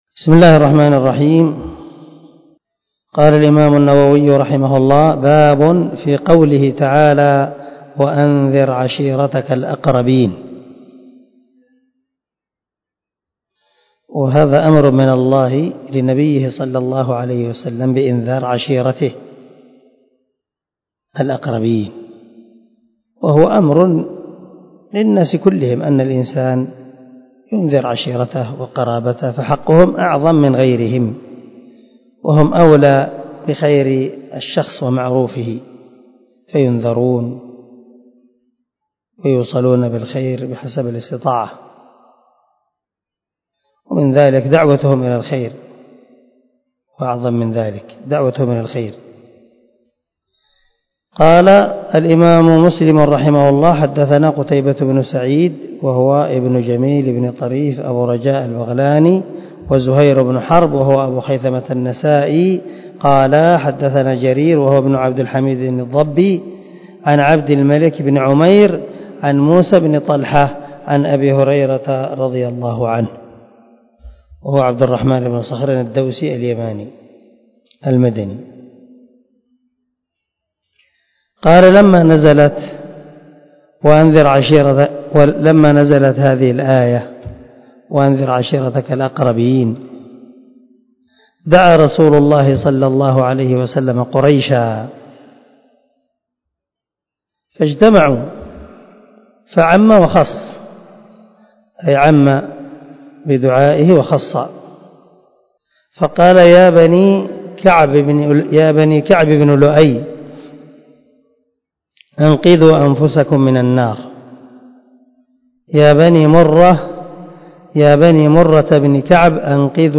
161الدرس 160 من شرح كتاب الإيمان حديث رقم ( 204 - 206 ) من صحيح مسلم